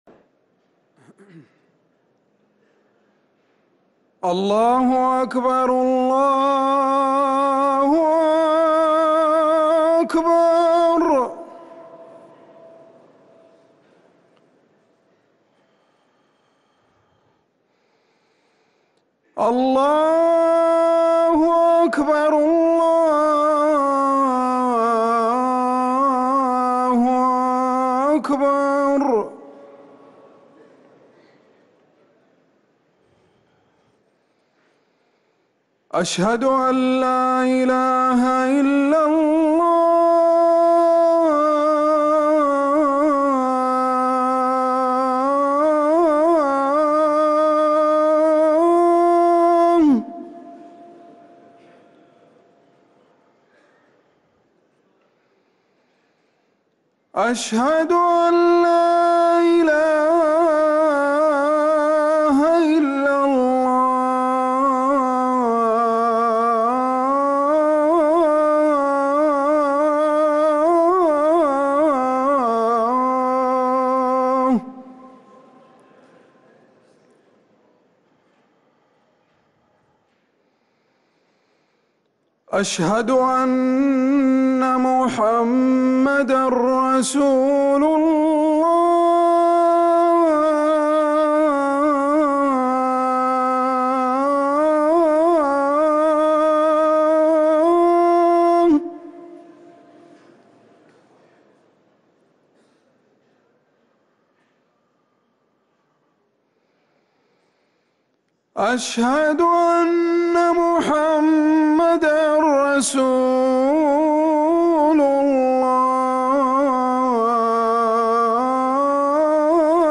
أذان العشاء للمؤذن عمر سنبل الأربعاء 15 ربيع الآخر 1444هـ > ١٤٤٤ 🕌 > ركن الأذان 🕌 > المزيد - تلاوات الحرمين